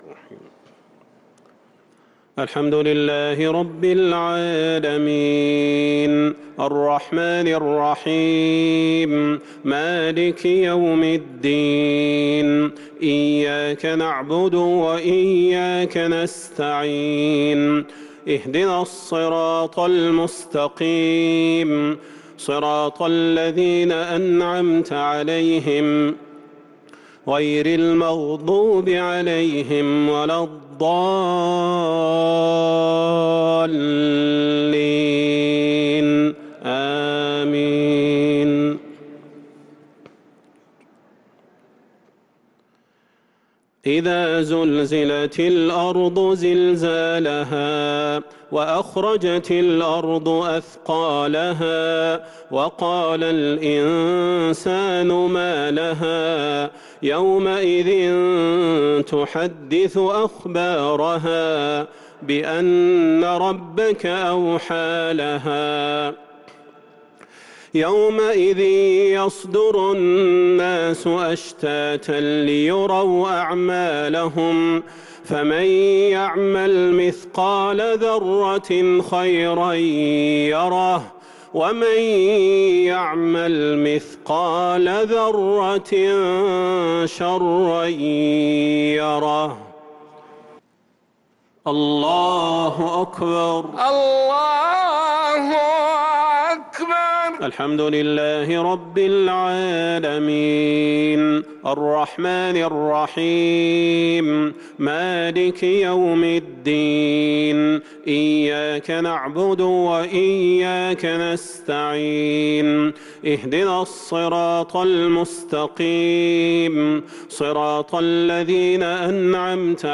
صلاة العشاء للقارئ صلاح البدير 1 رمضان 1443 هـ
تِلَاوَات الْحَرَمَيْن .